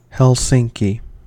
^ English: /ˈhɛlsɪŋki/ HEL-sing-kee or /hɛlˈsɪŋki/
hel-SING-kee,[10][11] Finnish: [ˈhelsiŋki] .
En-us-Helsinki.ogg.mp3